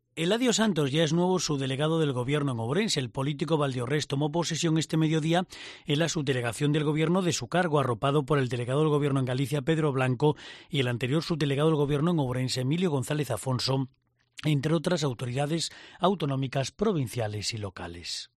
El nuevo Subdelegado del Gobierno en el acto de toma de posesión